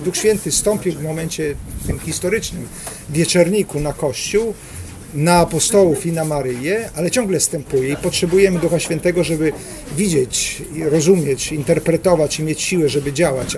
Mówi biskup sandomierski Krzysztof Nitkiewicz: